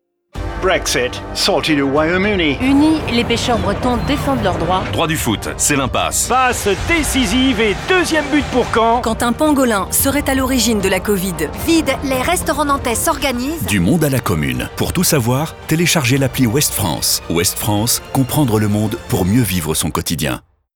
campagne de la nouvelle appli mobile de Ouest-France se décline en affichage, radio, presse et digital au sein de l’écosystème médiatique du quotidien.
OUEST-FRANCE-SPOT-APPLI-20-S.wav